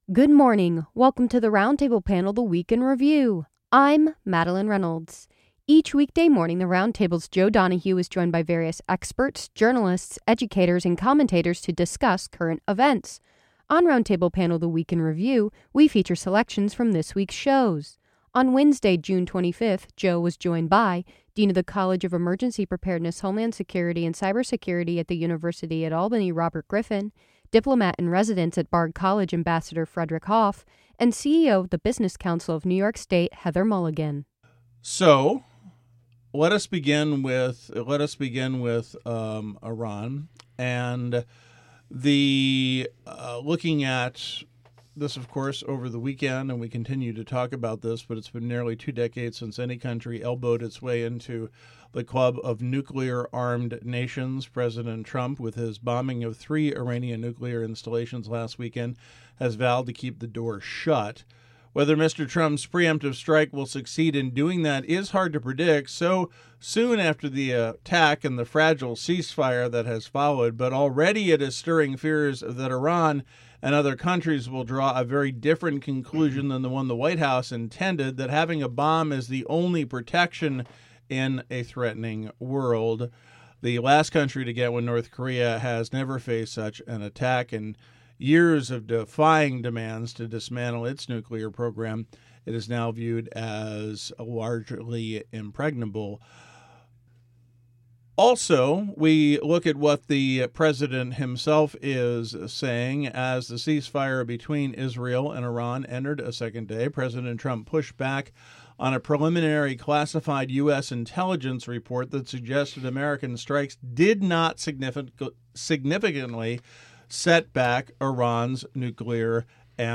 On Roundtable Panel: The Week in Review, we feature your favorite panelists discussing news items from the previous week.